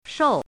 “狩”读音
shòu
狩字注音：ㄕㄡˋ
国际音标：ʂou˥˧
shòu.mp3